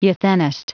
Prononciation du mot euthenist en anglais (fichier audio)
Prononciation du mot : euthenist